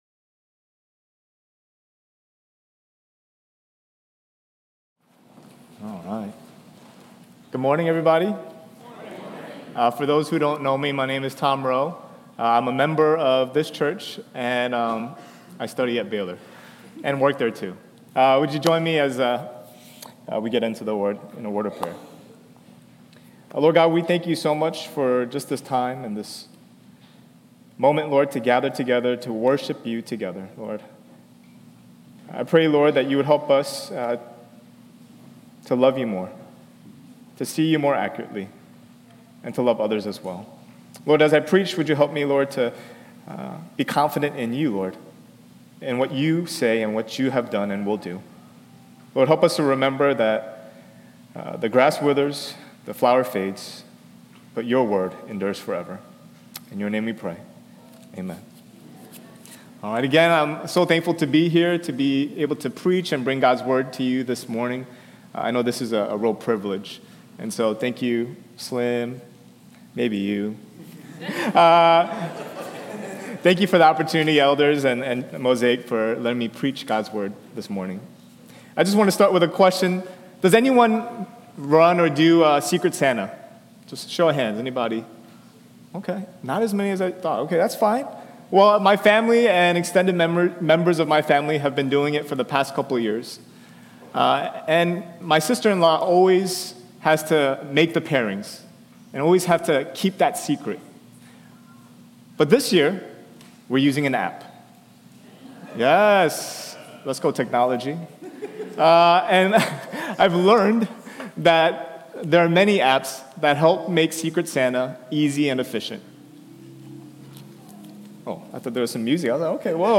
10AM Service Dec 7th 2025